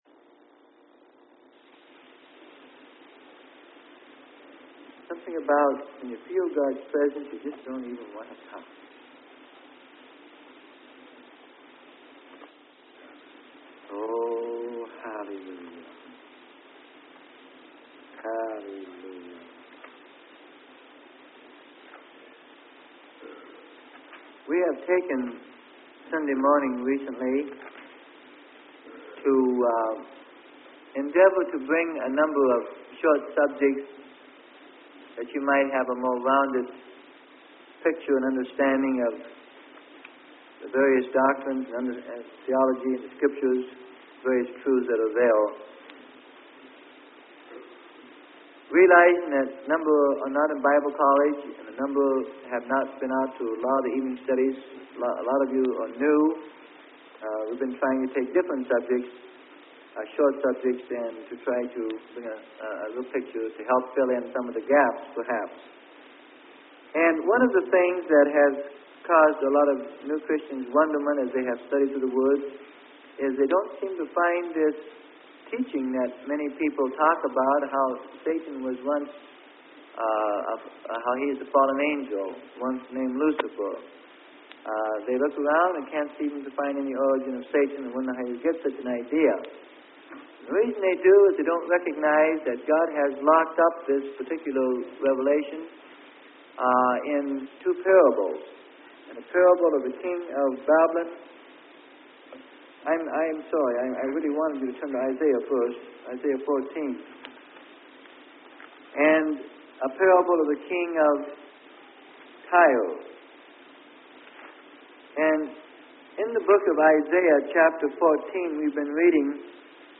Sermon: The Fall of Satan - Part 2 - Freely Given Online Library